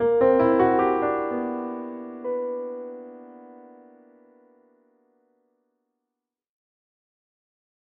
На пианино играю